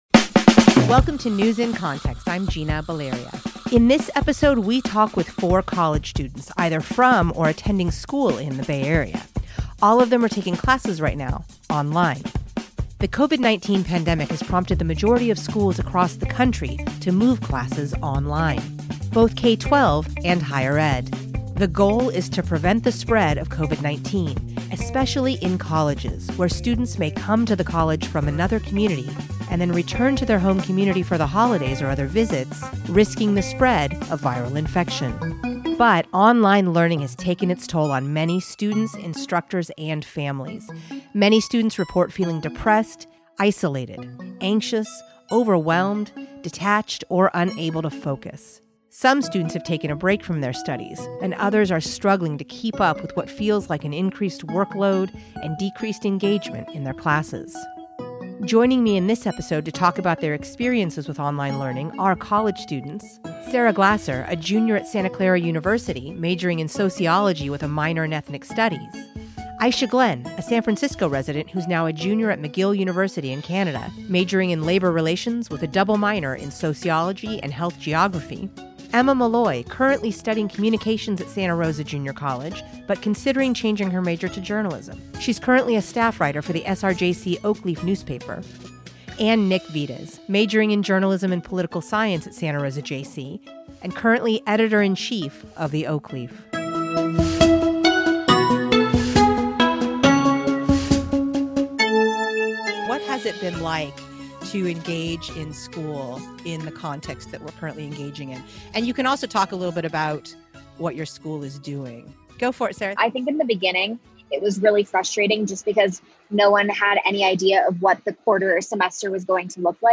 In this episode, we talk with four college students from and/or attending school in the Bay Area - all of them taking classes right now online.